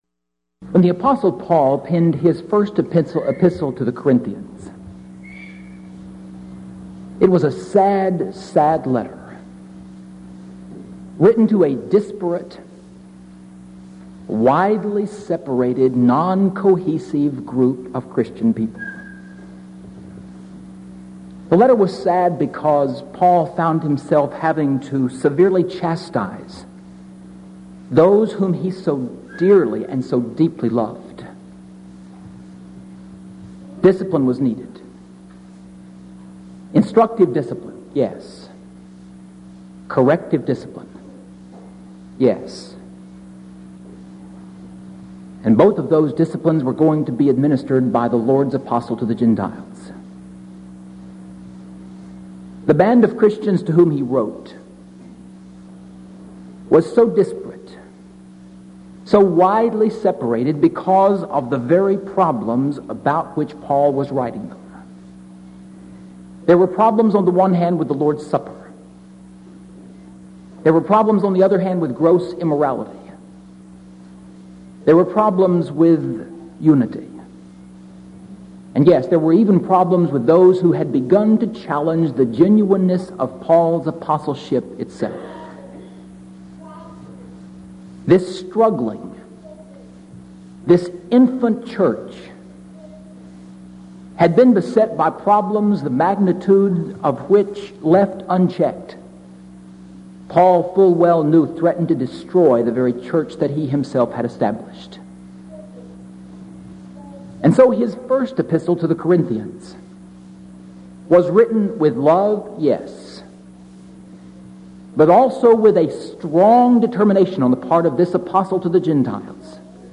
Event: 1989 Denton Lectures Theme/Title: Studies In The Book Of II Corinthians